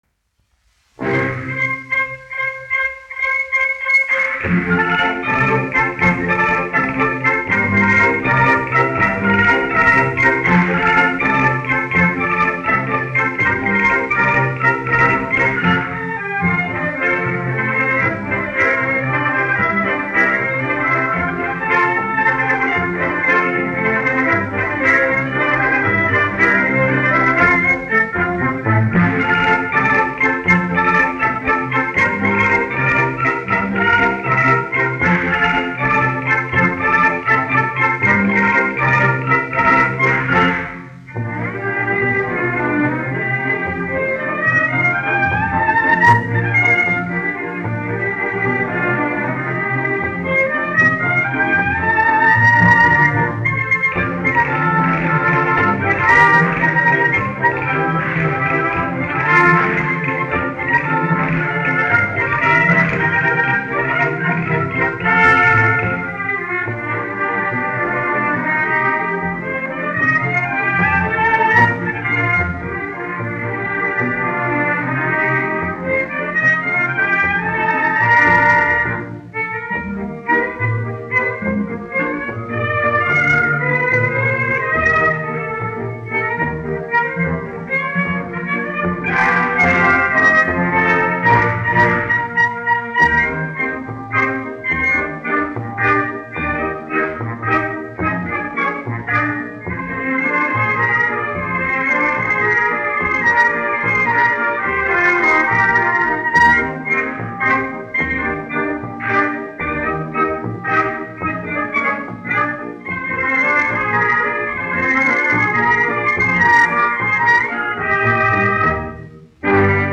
1 skpl. : analogs, 78 apgr/min, mono ; 25 cm
Valši
Orķestra mūzika
Latvijas vēsturiskie šellaka skaņuplašu ieraksti (Kolekcija)